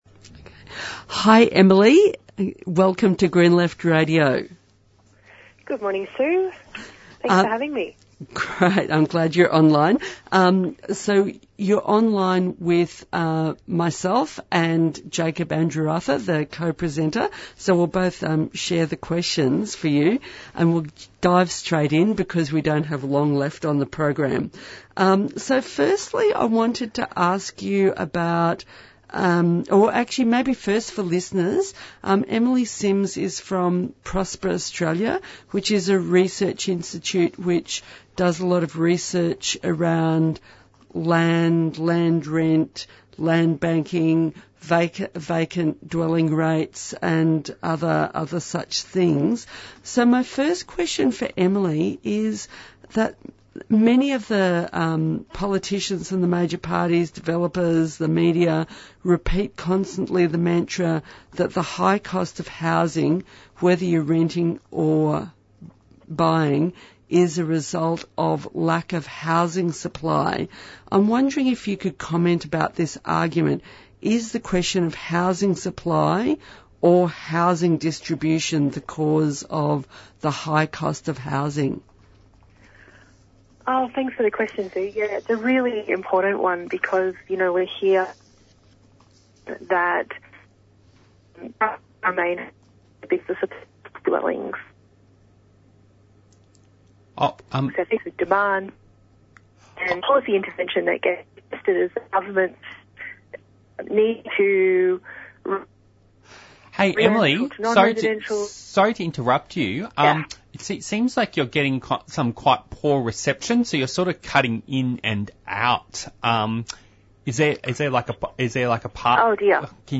Interviews and discussions